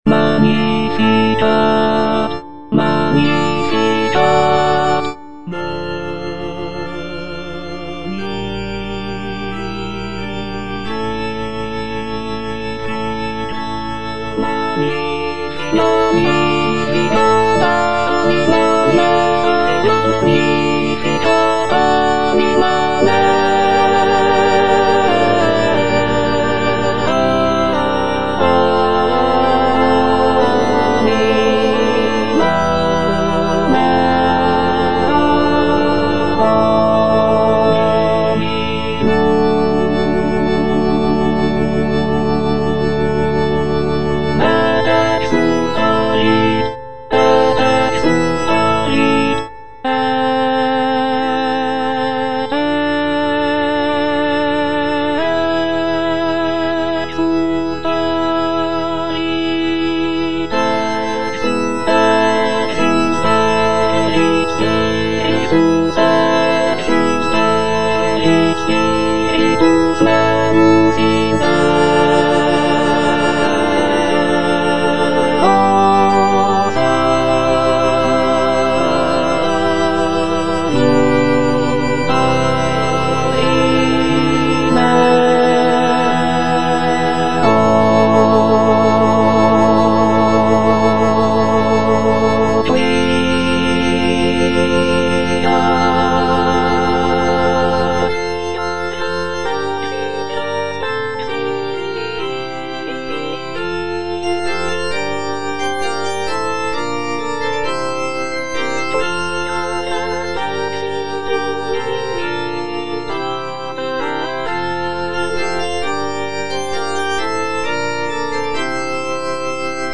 C. MONTEVERDI - MAGNIFICAT PRIMO (EDITION 2) Alto II (Emphasised voice and other voices) Ads stop: